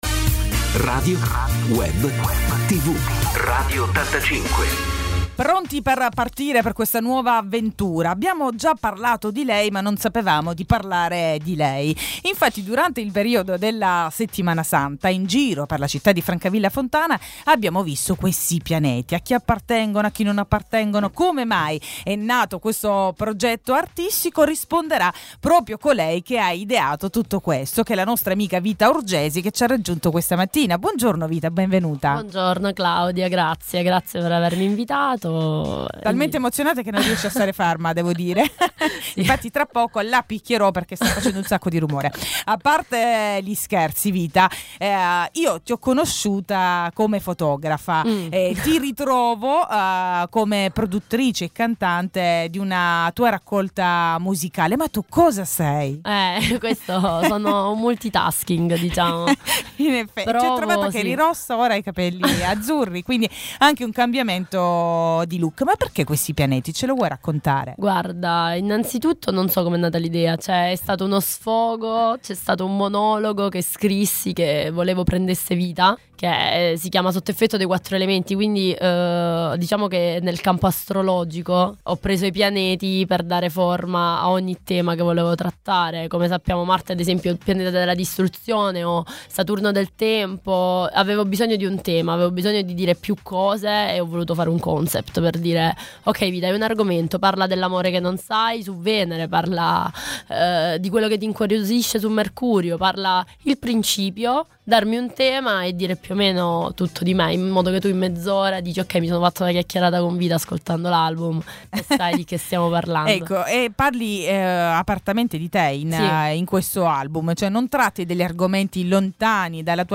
Curiosità, progetti e musica sono stati gli ingredienti della chiacchierata di oggi.